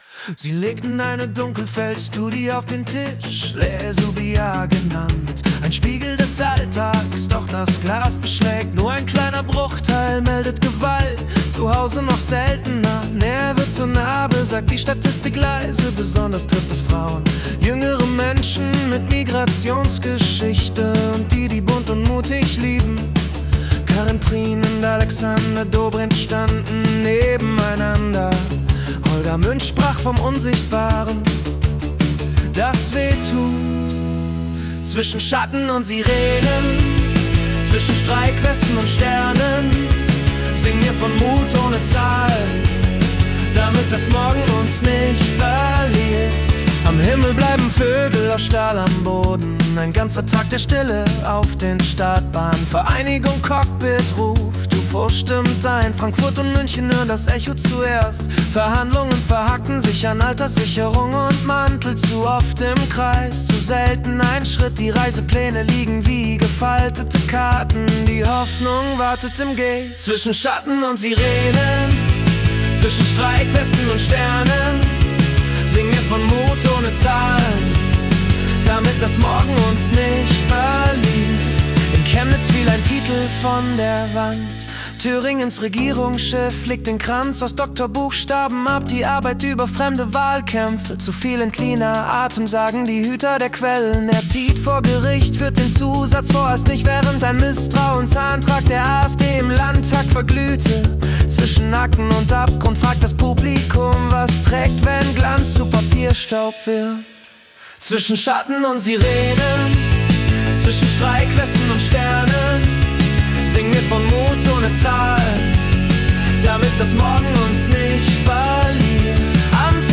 Die Nachrichten vom 11. Februar 2026 als Singer-Songwriter-Song interpretiert.